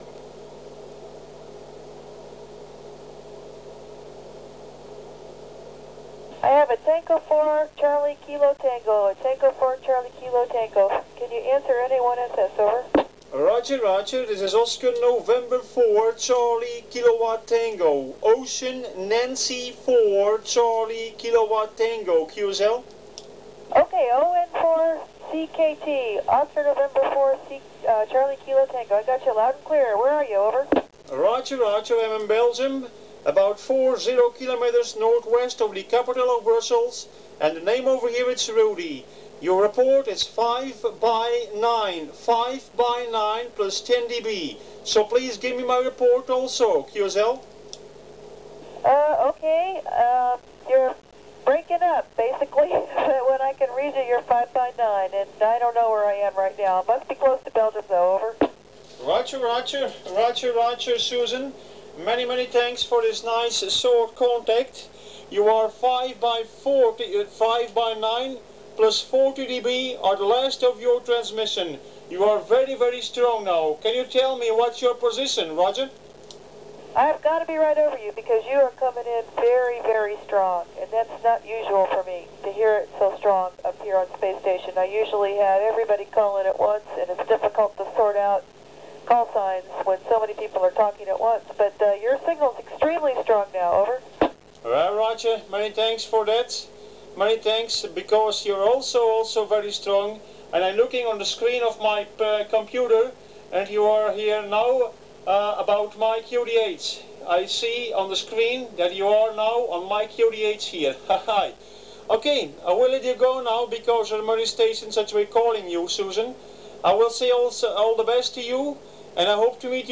My ISS and MIR voice contacts
My voice contact with astronaut Suzan Helms (NA1SS) on bord of the
International Space station on 24/06/2001   18:53u local time. Working
conditions, Kenwood TS-790E with 45 watt in a Cushcraft 13B2N antenna.